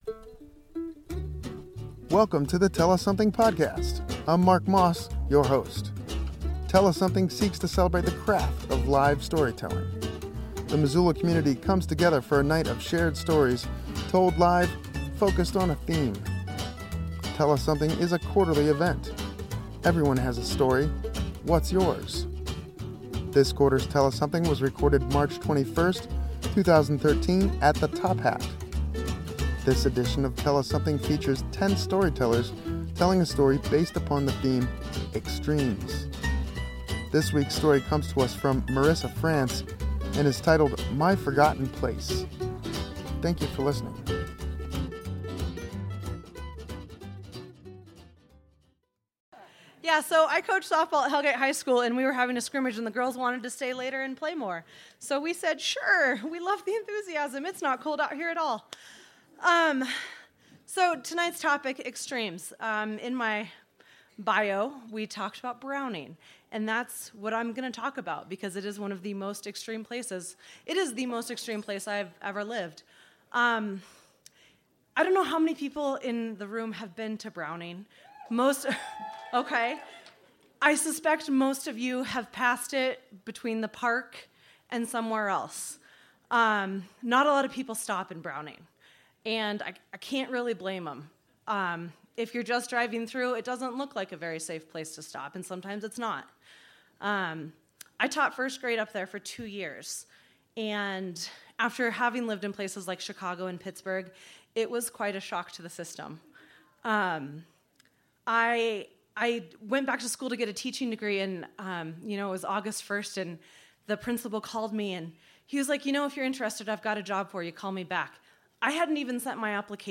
This edition of Tell Us Something was recorded on March 21, 2013 at the Top Hat Lounge in Missoula, MT. The theme was “Extremes”.